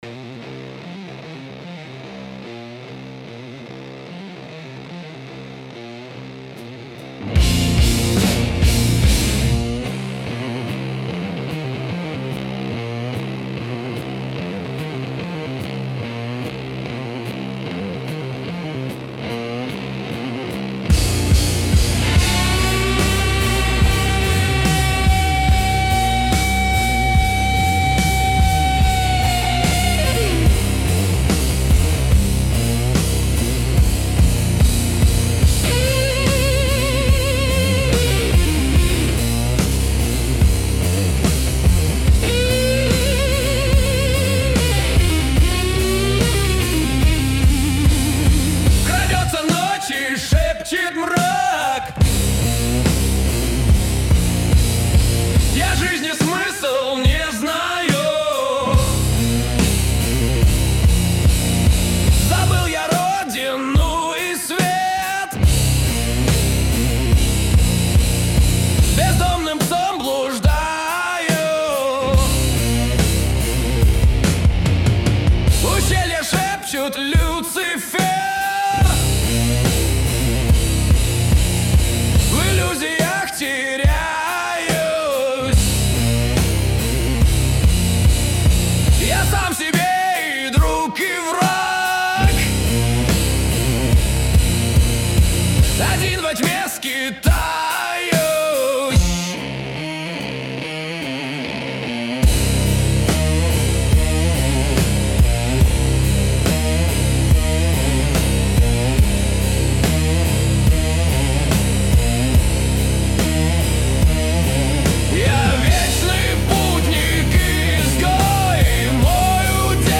• Песня: Лирика